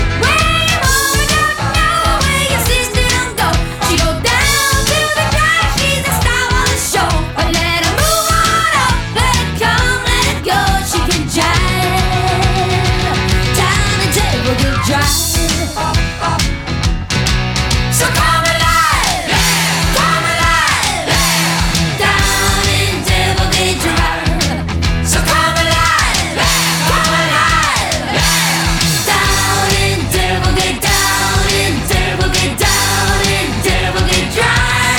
Жанр: Рок / Пост-хардкор / Хард-рок